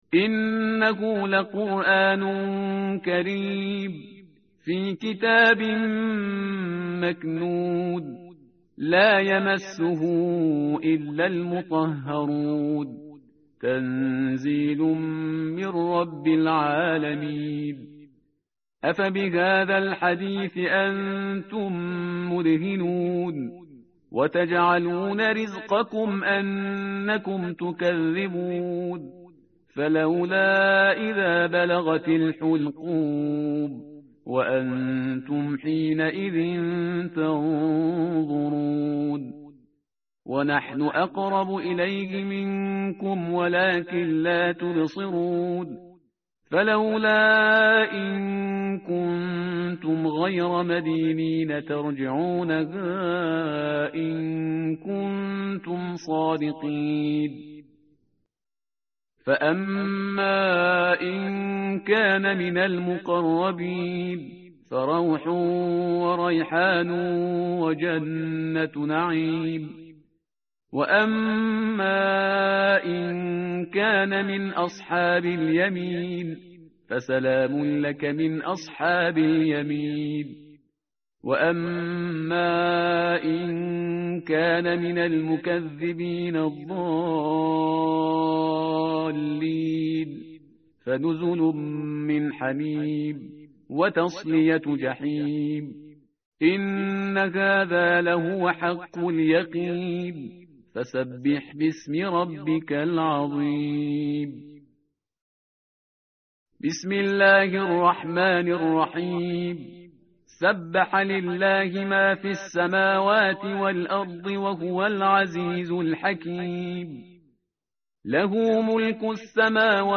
tartil_parhizgar_page_537.mp3